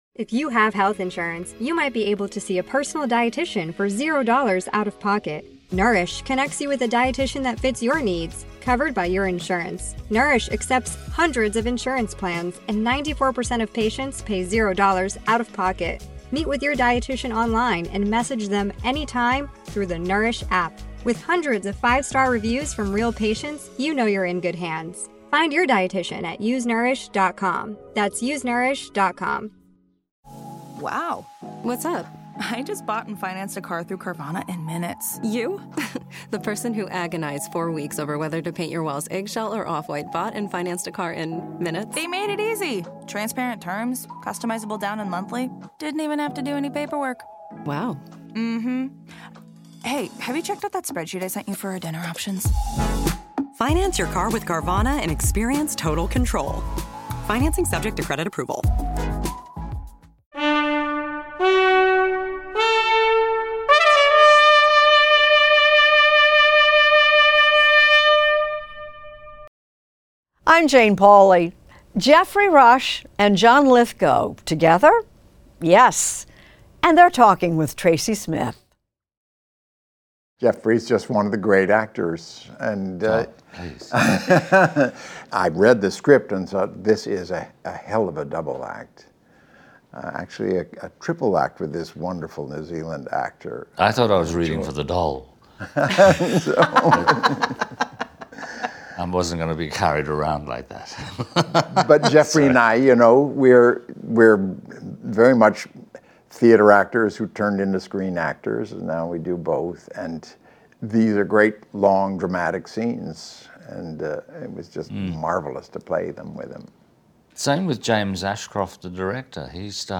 Extended interview: Geoffrey Rush and John Lithgow
Two of our most celebrated stage and screen actors, Geoffrey Rush and John Lithgow, are starring in a new horror film, "The Rule of Jenny Pen," about a psychopath who terrorizes his fellow nursing home residents with a sinister doll puppet. In this extended conversation, correspondent Tracy Smith talks with Rush and Lithgow about the theatrical, and horrific, aspects of their film; what it was like to be the youngest members of their cast; and the family life of actors.